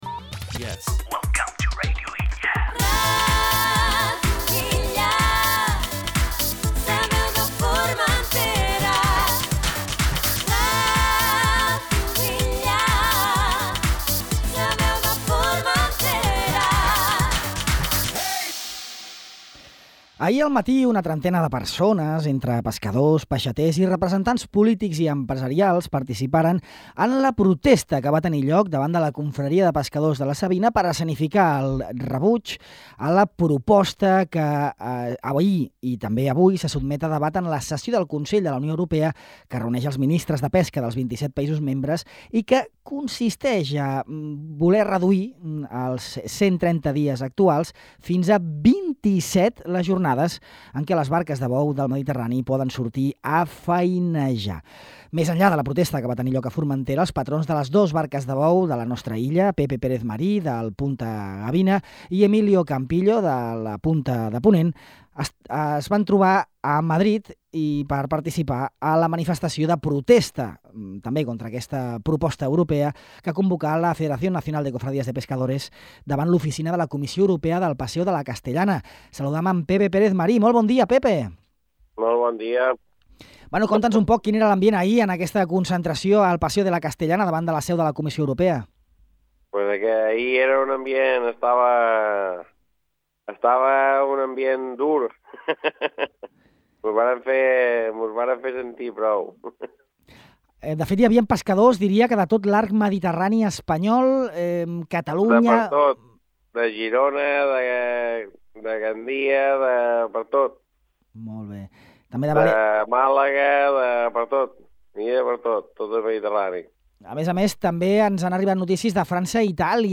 Escolta l’entrevista sencera en aquest reproductor.